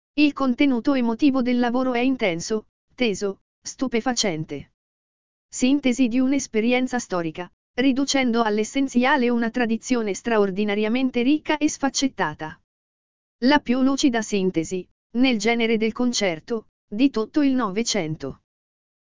Concerto op.42 Playlist ▾ Presentazione Andante, Allegro molto, Adagio, Giocoso